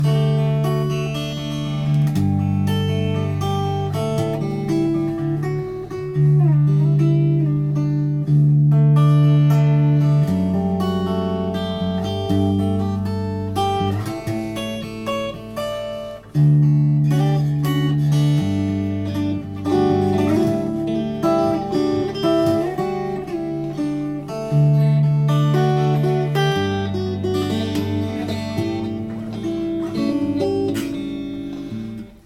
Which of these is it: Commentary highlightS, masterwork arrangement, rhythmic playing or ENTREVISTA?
rhythmic playing